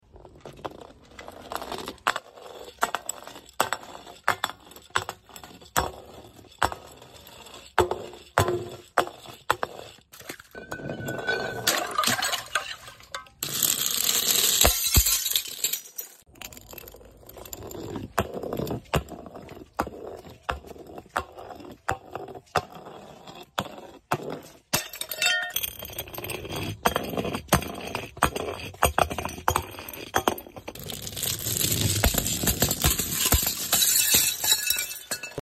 Breaking#bottles#amazing#vedios#satisfying# video#amazing#asmrvideo#ASME#usa tiktok#us#unitedstates For you sound effects free download